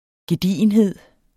Udtale [ geˈdiˀənˌheðˀ ]